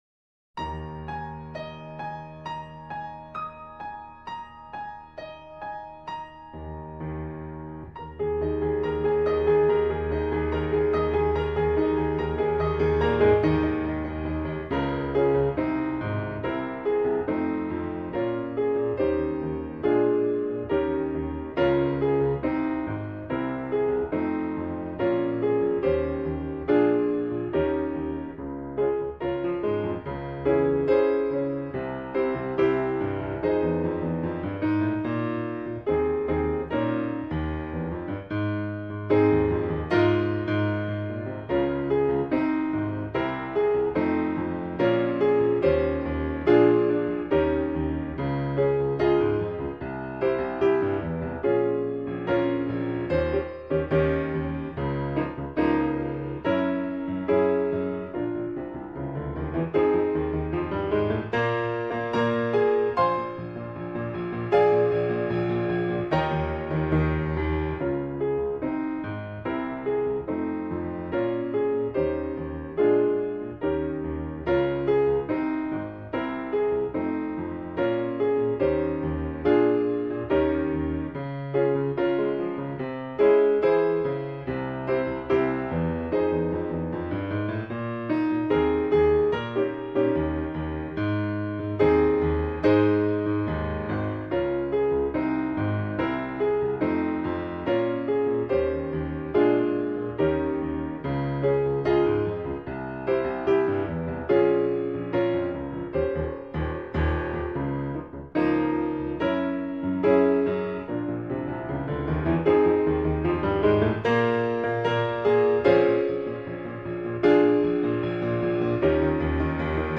เพลงพระราชนิพนธ์, เปียโน